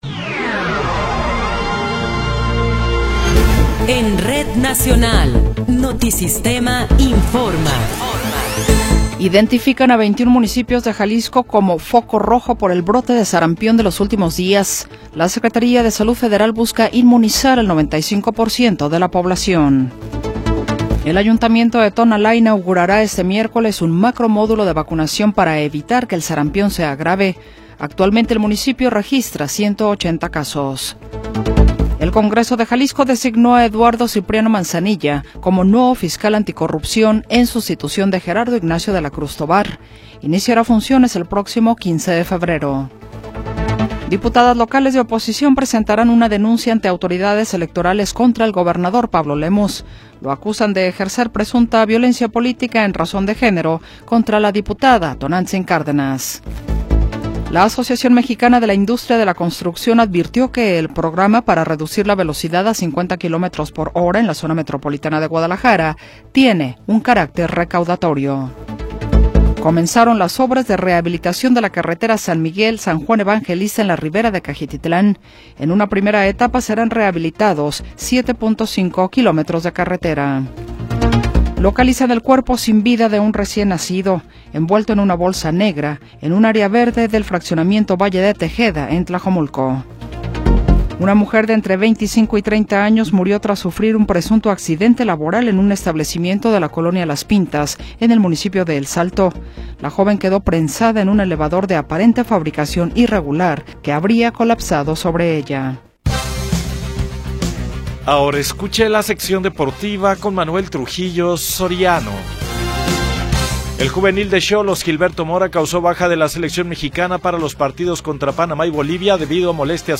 Noticiero 21 hrs. – 20 de Enero de 2026